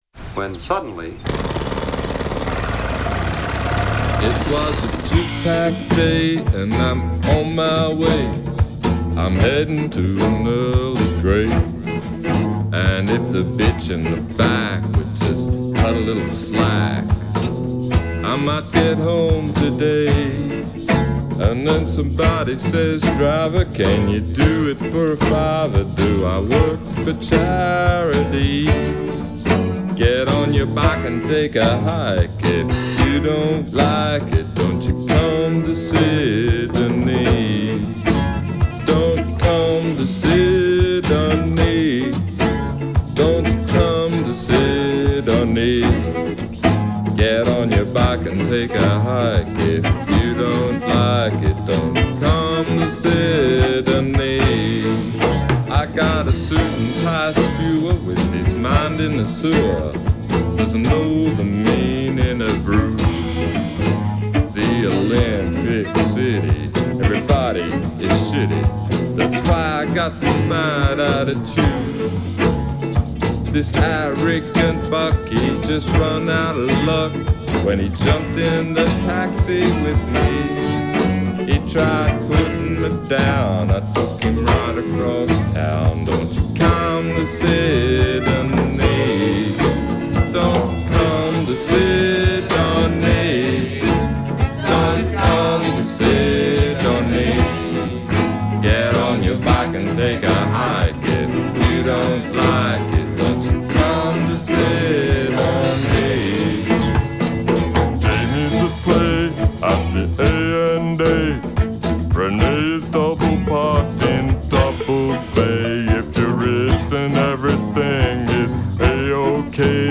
vocals, guitar
violin